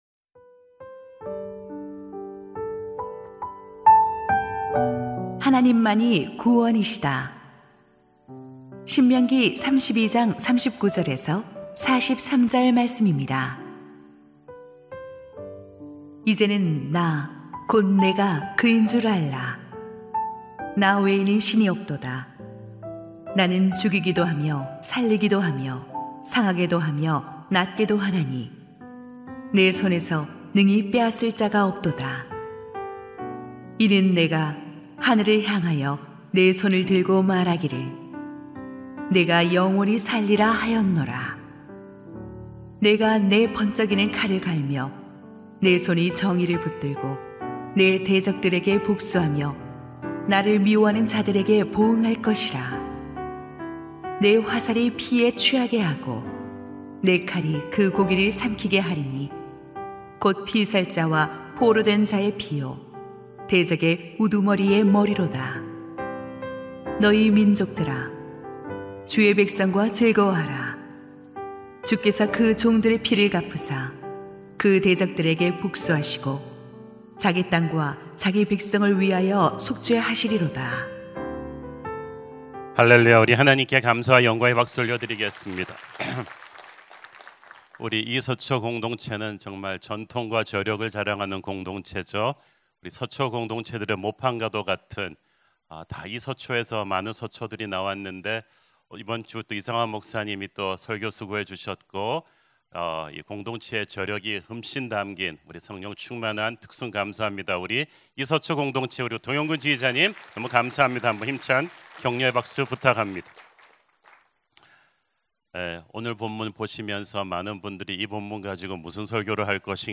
2026-03-21 공동체 주관 새벽기도회
[새벽예배]